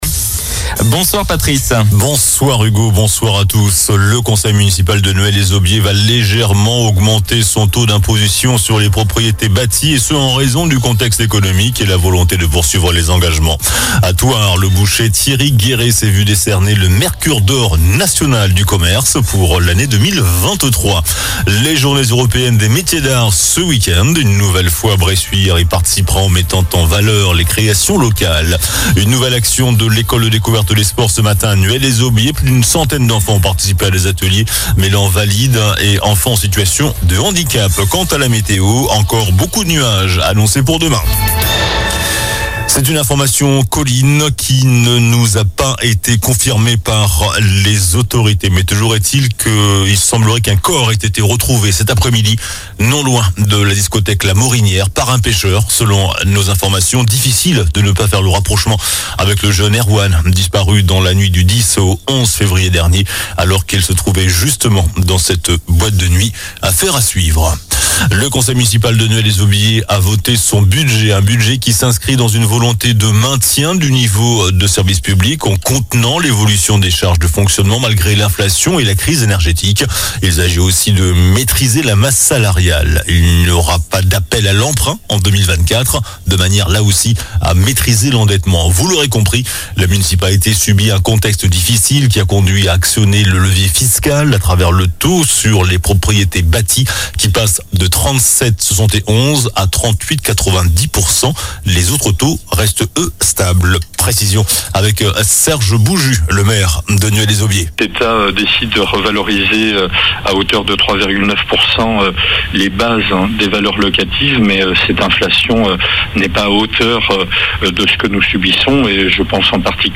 JOURNAL DU JEUDI 04 AVRIL ( SOIR )